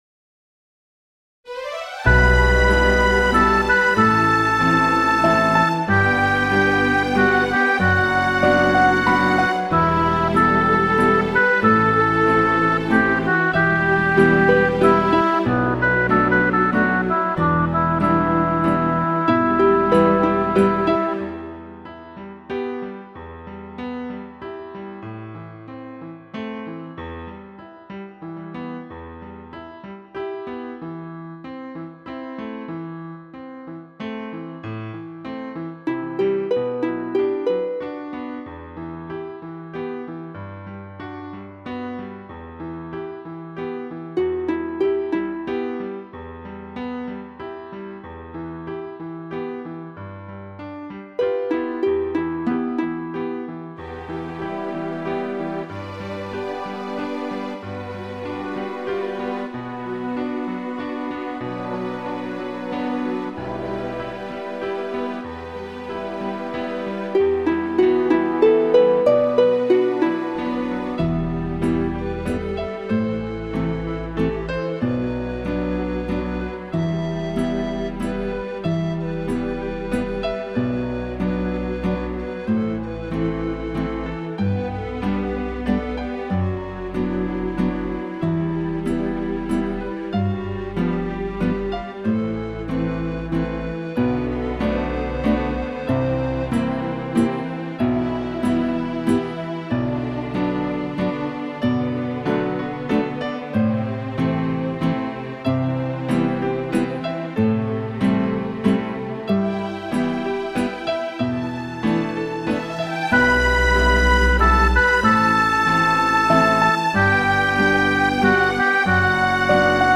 原創歌曲《雪花 ,你是否能為我們留下》